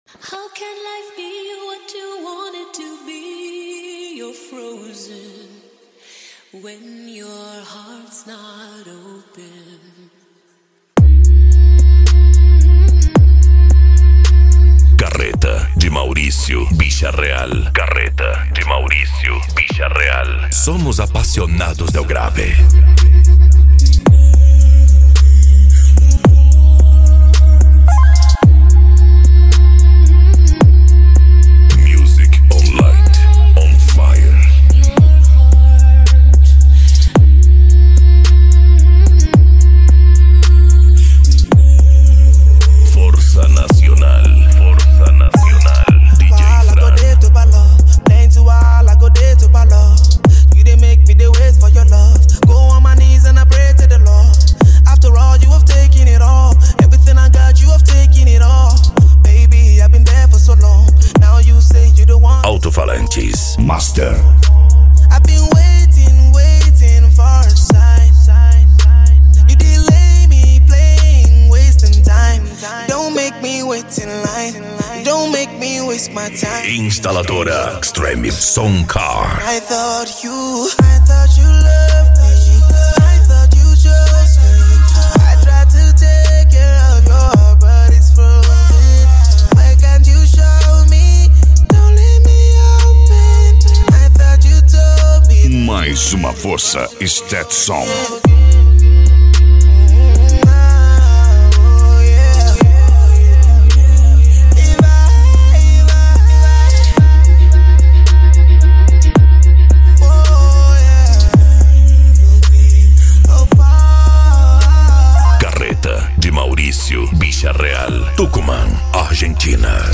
Bass
PANCADÃO
Psy Trance
Remix